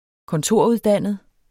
Udtale [ kɔnˈtoˀɐ̯ˌuðˌdanˀəð ]